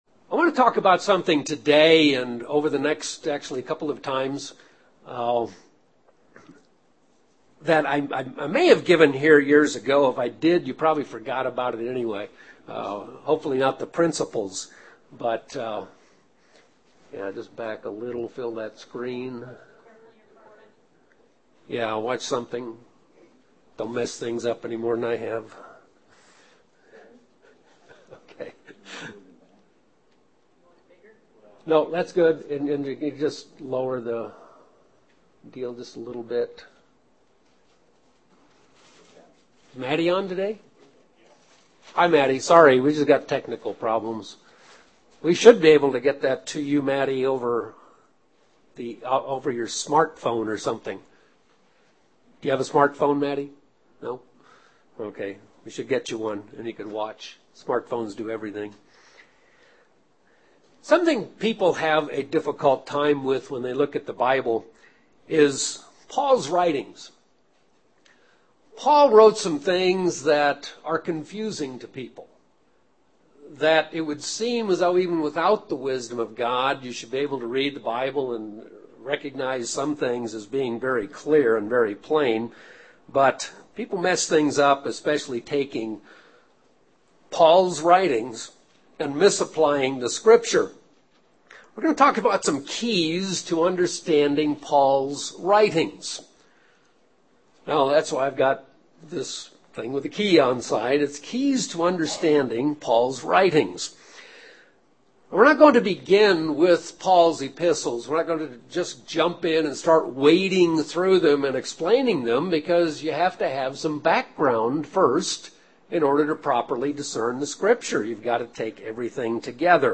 Many Christians today attempt to use the writings of the Apostle Paul to do away with the commandments of God, touting a "grace only" salvation. This sermon will discuss Paul's writings in light of the rest of scripture - enabling us to understand what Paul was - and was not saying.
Given in Albuquerque, NM